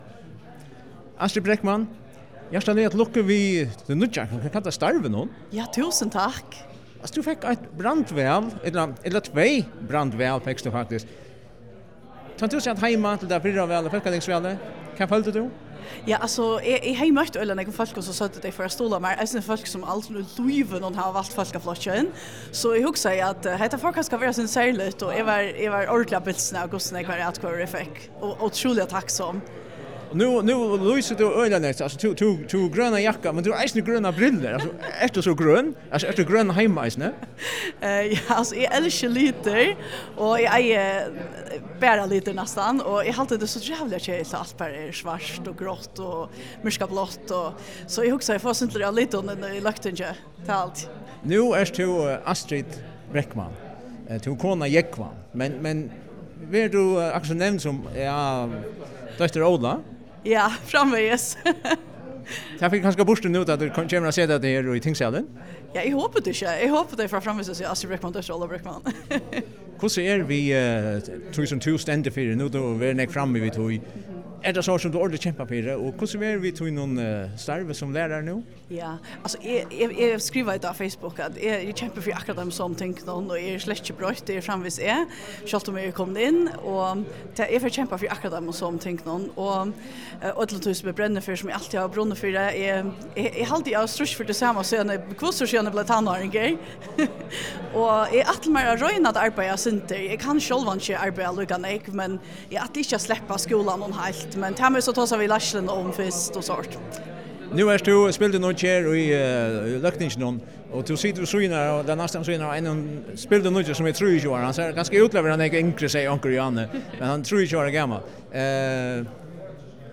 Hoyr Astrid Breckmann seta orð á nú hon tekur sætið í tingsalinum.